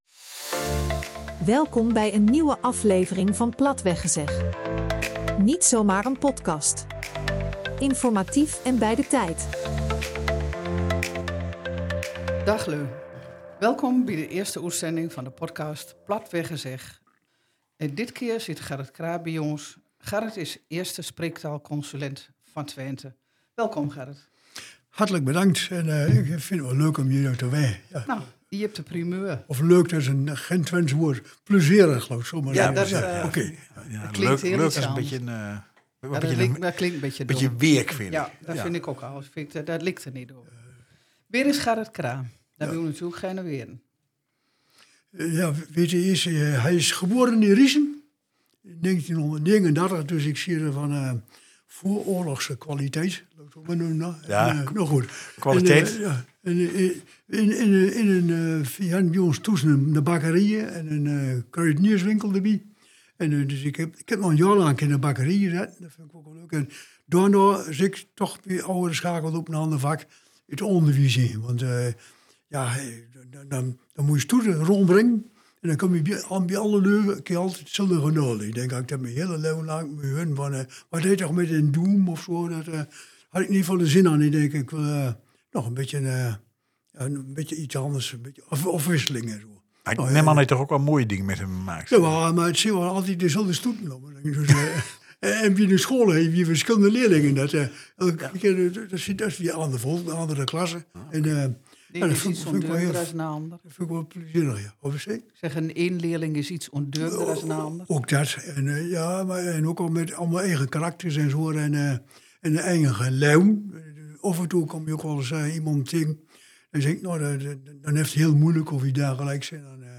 Het team van Platweggezeg blijft zich met actuele onderwerpen ook sterk maken voor een goed gebruik van het dialect.